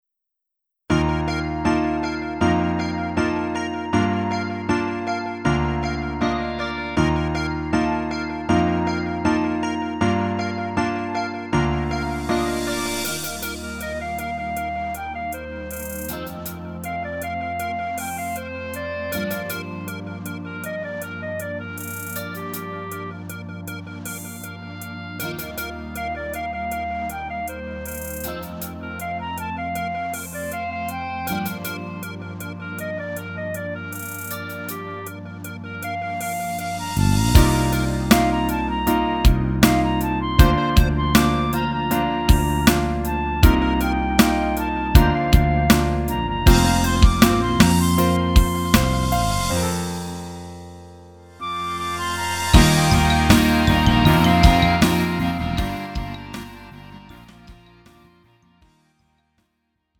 음정 원키 3:29
장르 가요 구분 Lite MR